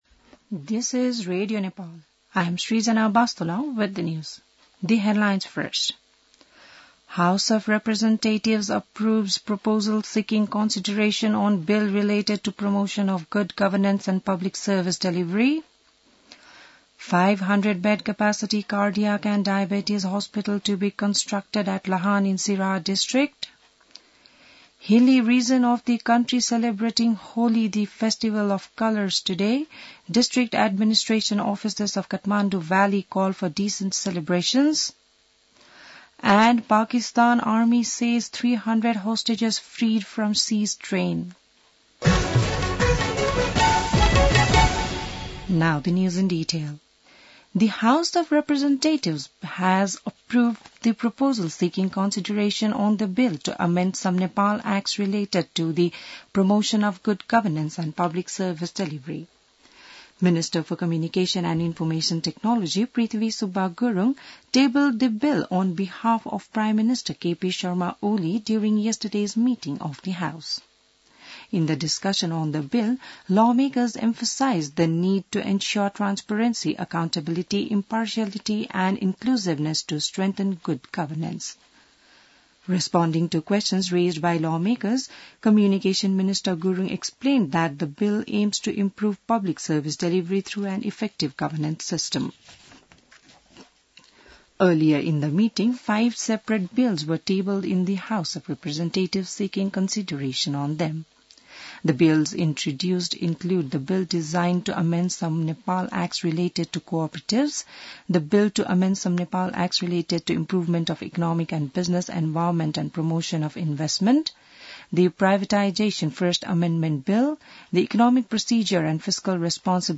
An online outlet of Nepal's national radio broadcaster
बिहान ८ बजेको अङ्ग्रेजी समाचार : ३० फागुन , २०८१